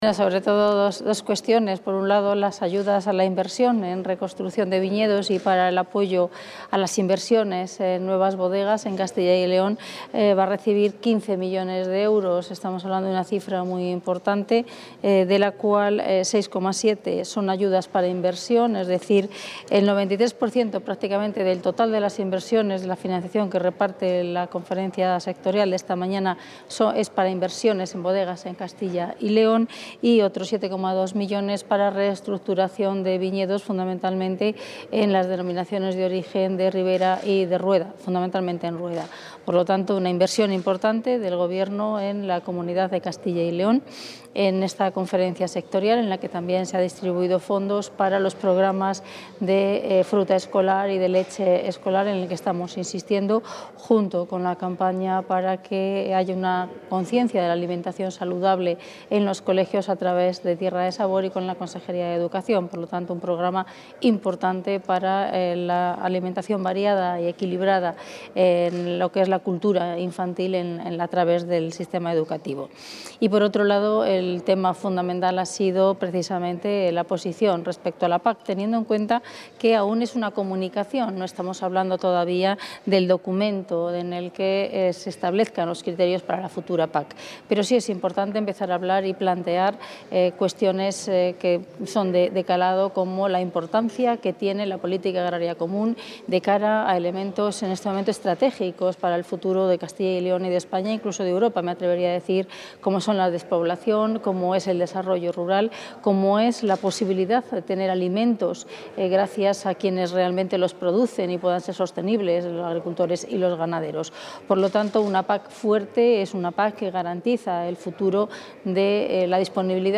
Declaraciones de la consejera de Agricultura y Ganadería.